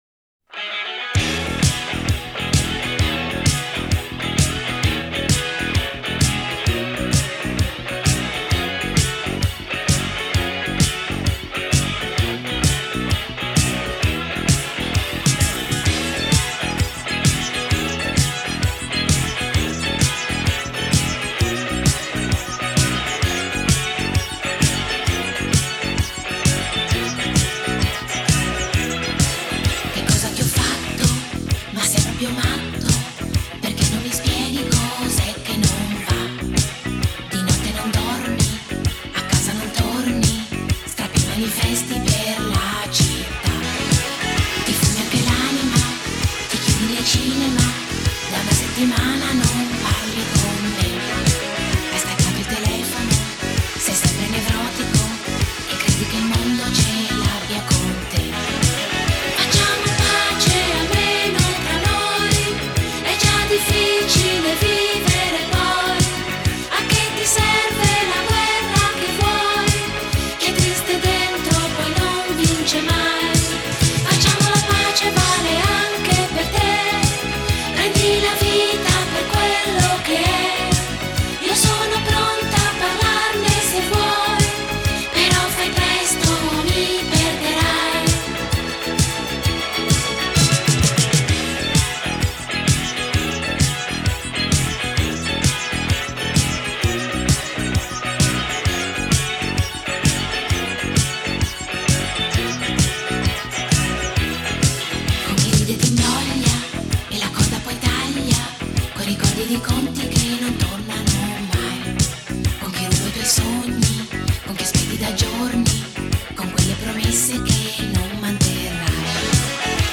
Genre: Pop, Disco,Nu-Disco,Dance